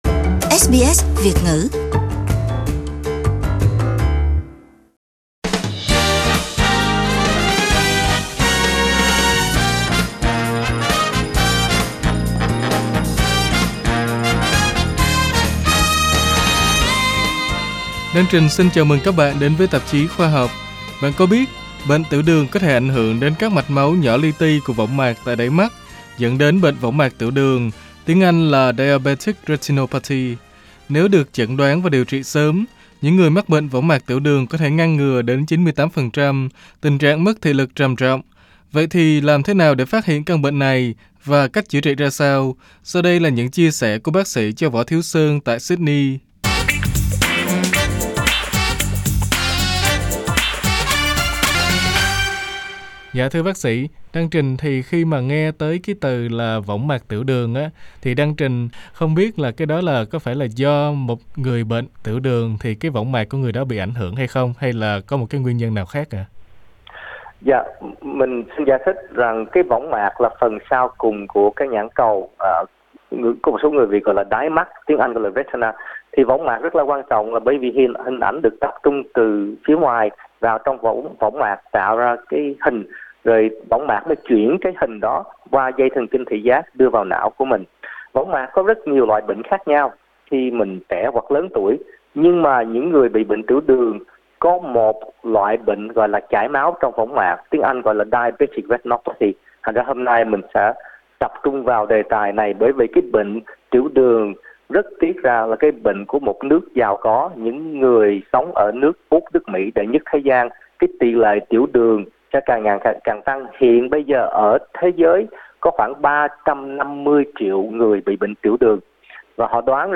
SBS Vietnamese phỏng vấn bác sĩ nhãn khoa